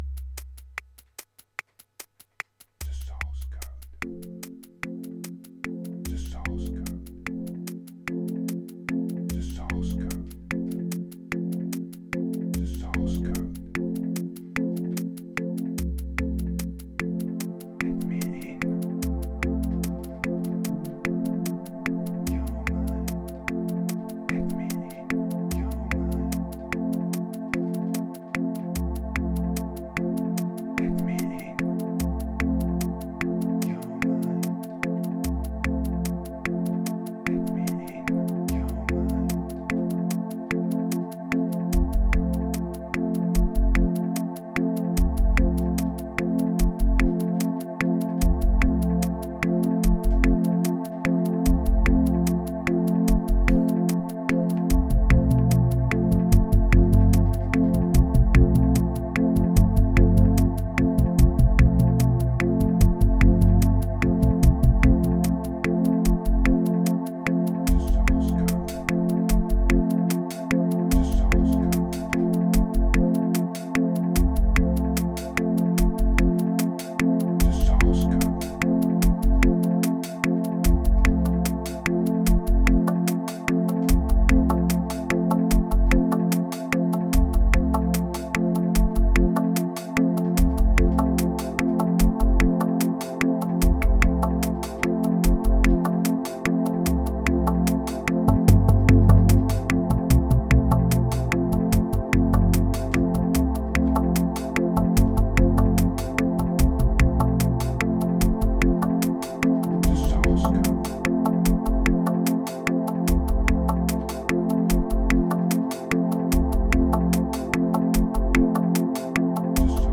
Prepare your subwoofer or your deep headz.
Ambient Chillout Hush Riddim Moods Chords